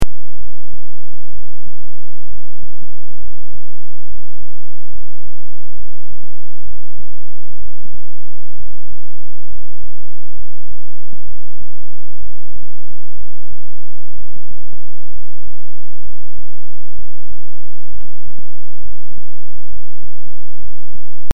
Raw Signal